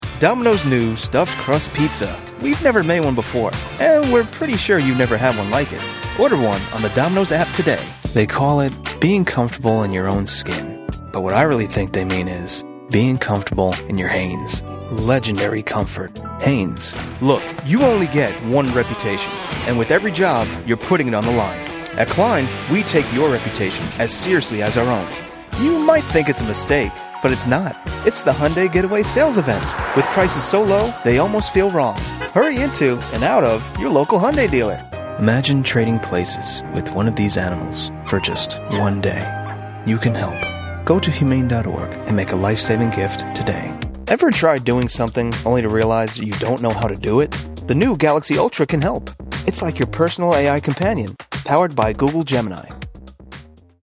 Male Voice Over Talent